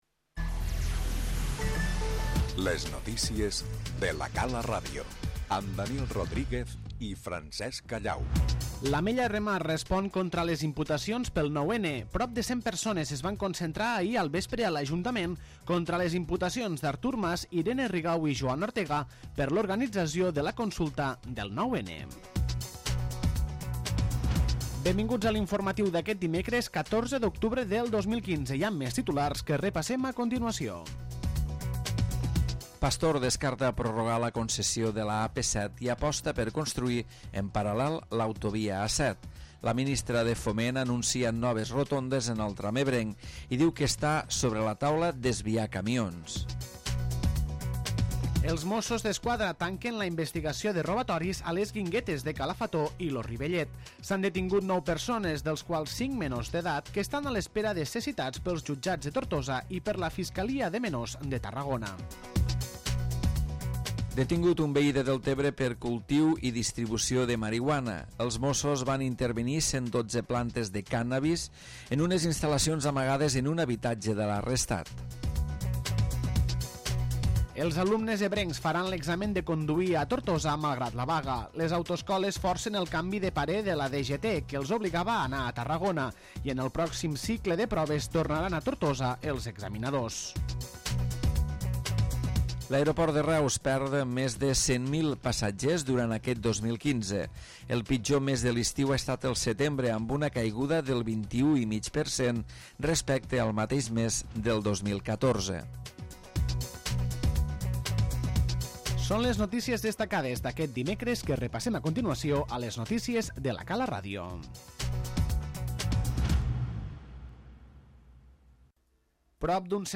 L'informatiu de la Cala Ràdio d'aquest dimecres, centrat amb la concentració en contra de les imputacions pel 9-N, l'anunci de la Minsitera de Foment respecte l'N-340, i les detencions per robatoris a guinguetes.